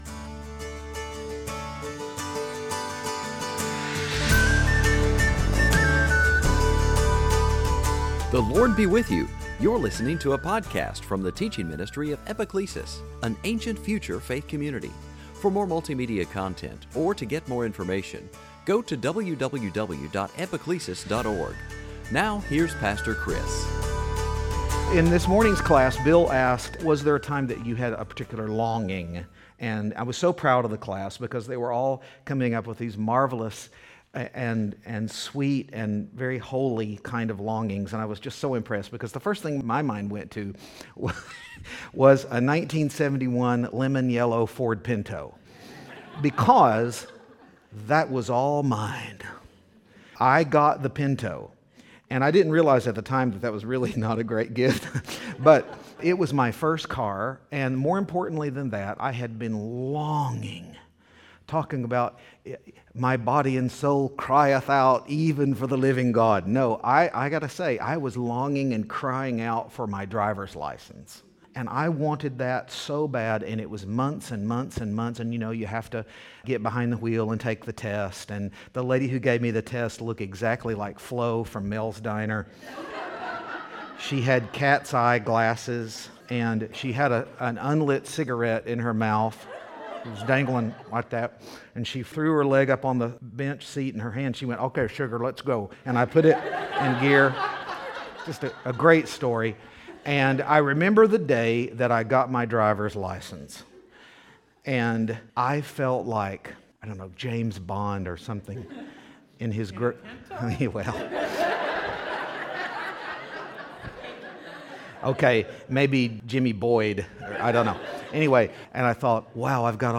Series: Sunday Teaching Along the way during Lent our church is looking at various paths that we walk